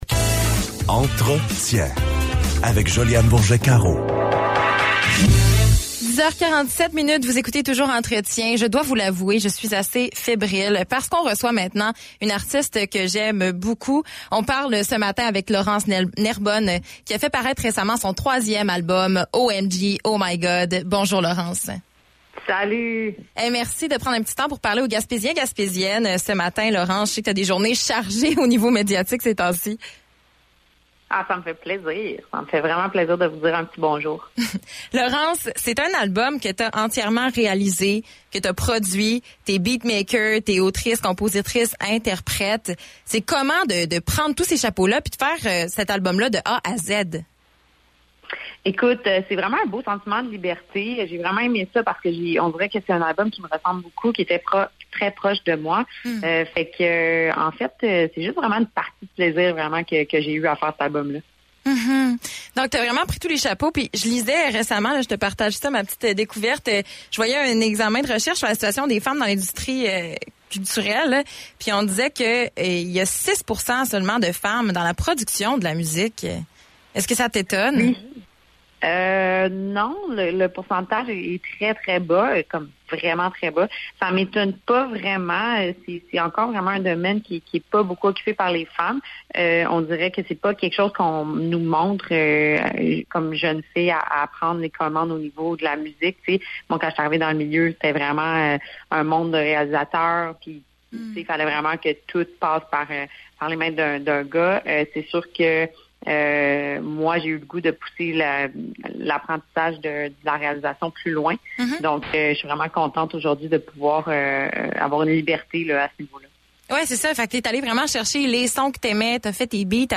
entrevue_laurence_nerbonne.mp3